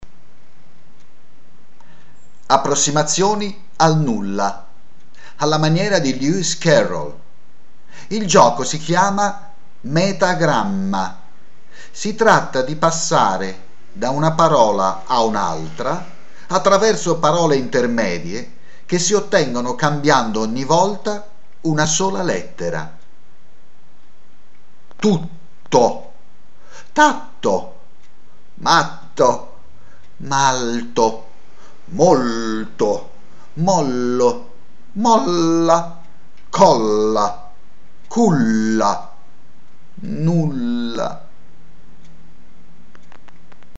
KLANG! suoni contemporanei, due interventi: Piccolo campionario di suoni bianchi (0'57") e
Approssimazioni al nulla (0' 42"), Villa Paolina, Viareggio, 7 agosto.